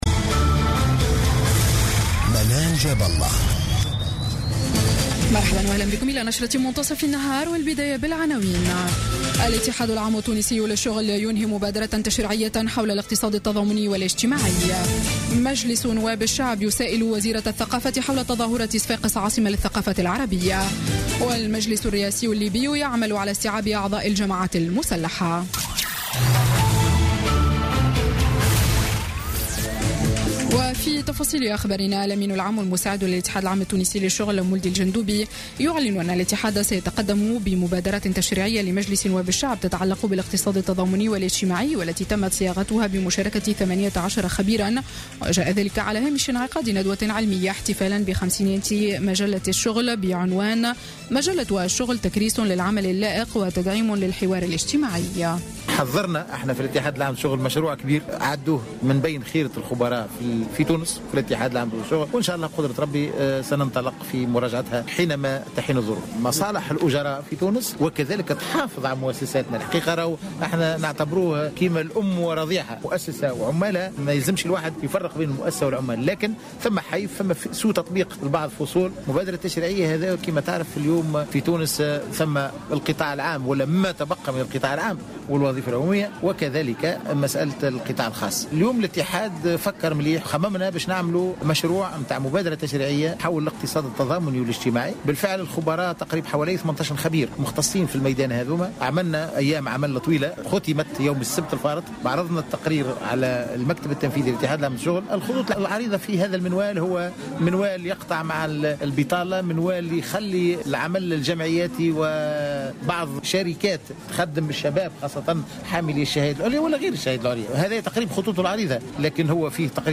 نشرة أخبار منتصف النهار ليوم الثلاثاء 10 ماي 2016